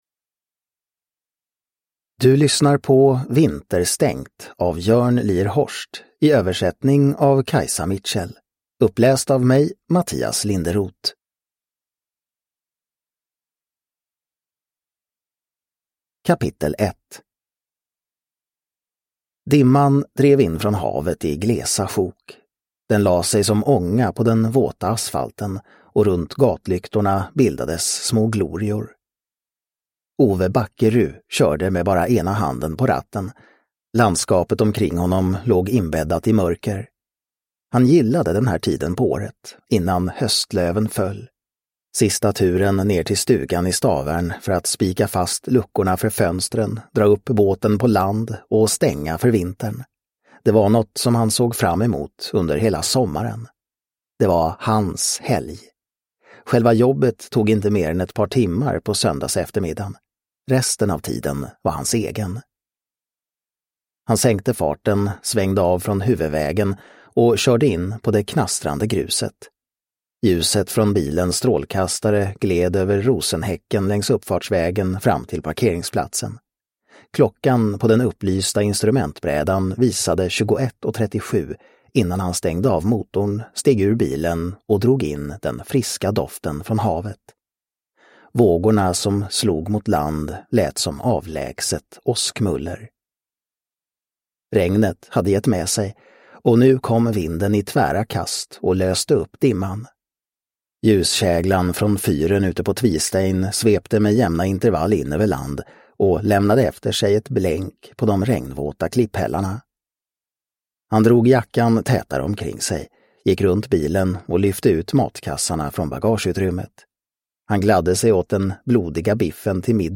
Vinterstängt – Ljudbok – Laddas ner